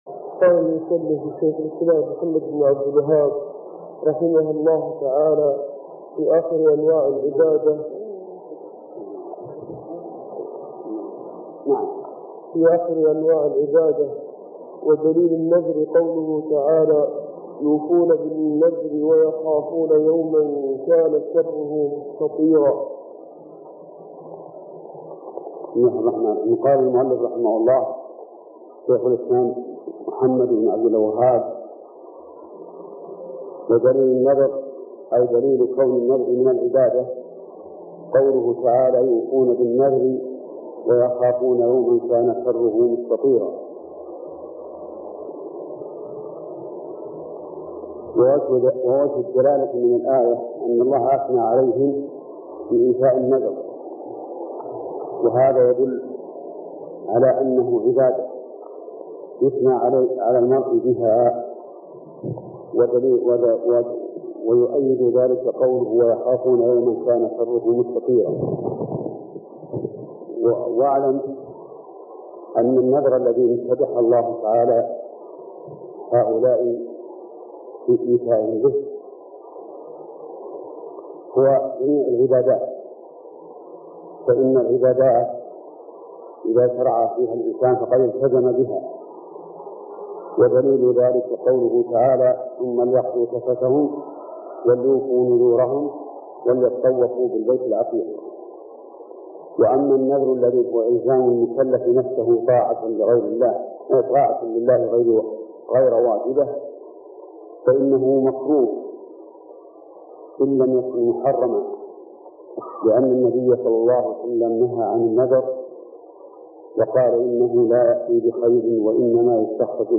الدرس الرابع: من قوله: (ودليل الخشية ..، إلى قوله:ومعنى شهادة أن محمد رسول الله.